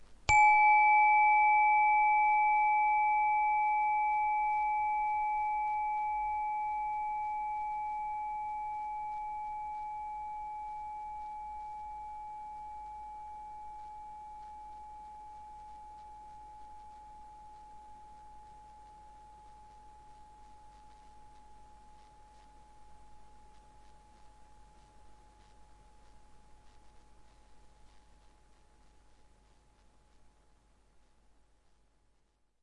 Solfeggio 能量棒钟 " Onesolopur Solfeggio852 Hzwith Wood
这是用木槌处理Chime Solfeggio 852 Hz的单音。
Tag: 视唱练耳-LA-852赫兹 852赫兹 冥想换提高意识 觉醒 直觉 PUR-独奏声-的 视唱练耳 与能量吧 编钟 返回到精神订单